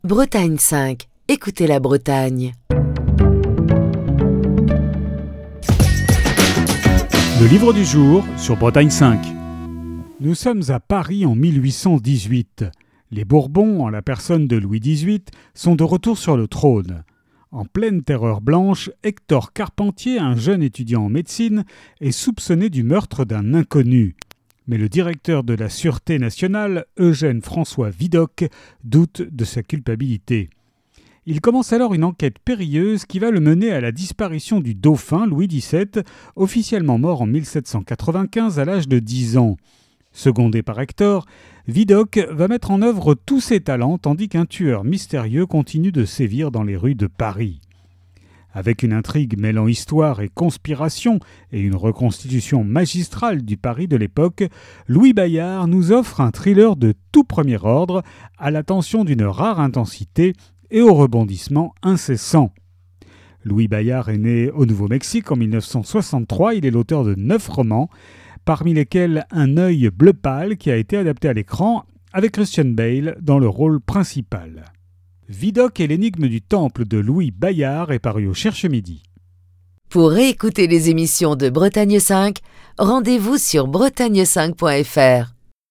Chronique du 12 juin 2023.